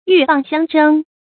注音：ㄧㄩˋ ㄅㄤˋ ㄒㄧㄤ ㄓㄥ
鷸蚌相爭的讀法